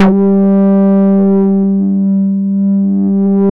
Index of /90_sSampleCDs/Trance_Explosion_Vol1/Instrument Multi-samples/Wasp Bass 2
G3_WaspBass2.wav